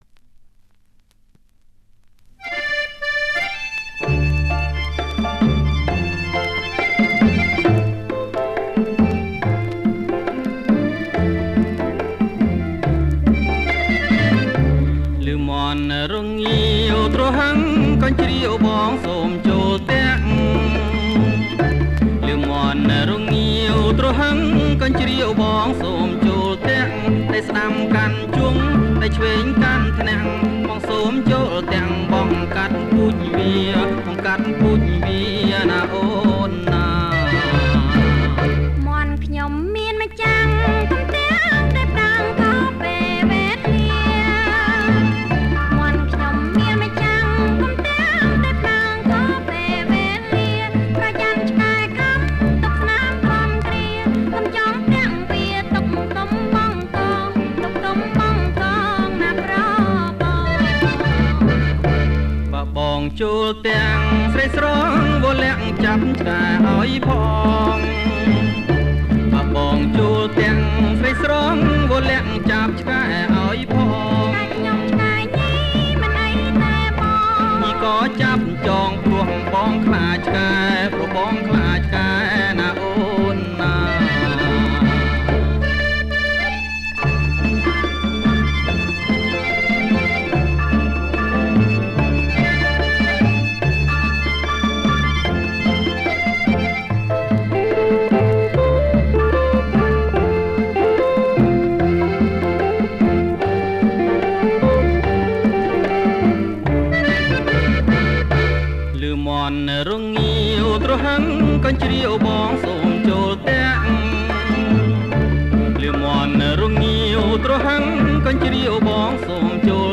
• ចង្វាក់ រាំក្បាច់